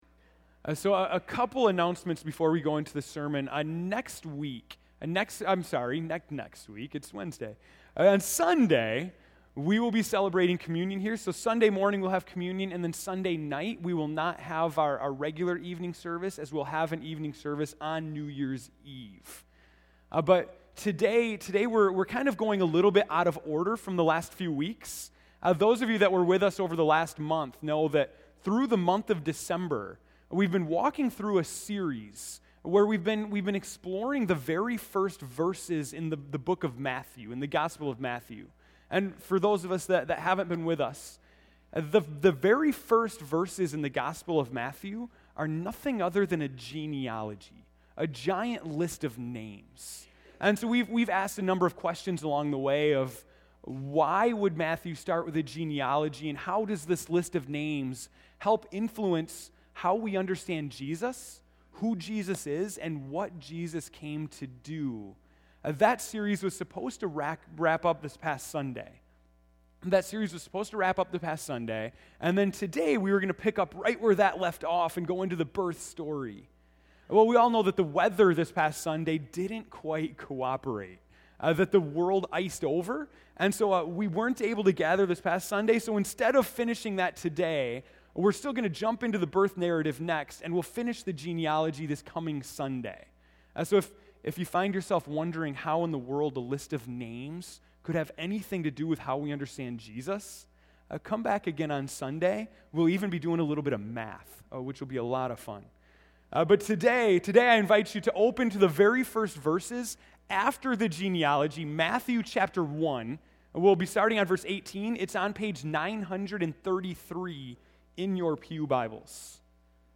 December 25, 2013 (Morning Worship)